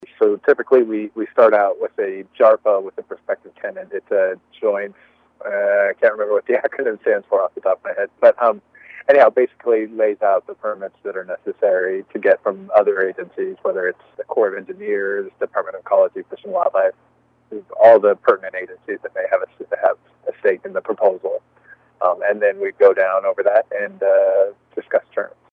We asked him to give us a brief description of the application process.